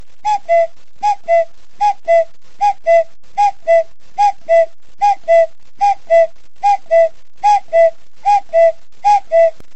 Kategorie: Klingeltöne